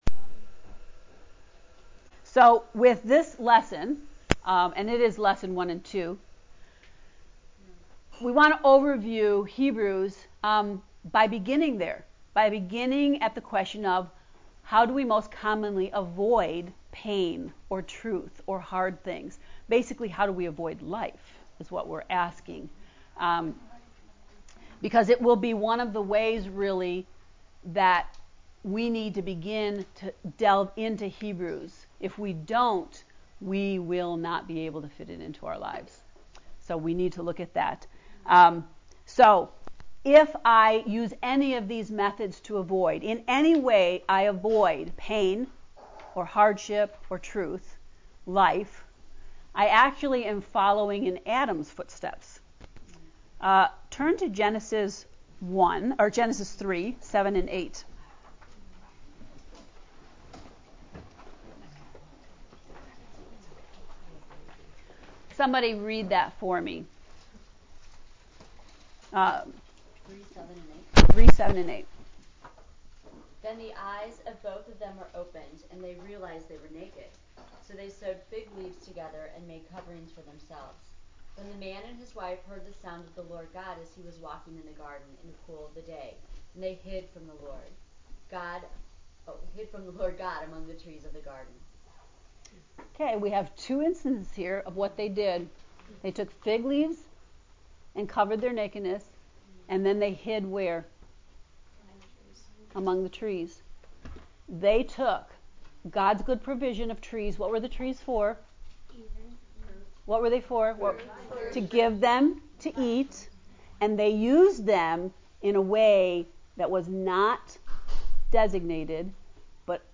Hebrews Lecture 1 & 2